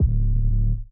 SouthSide Dirty Kick.wav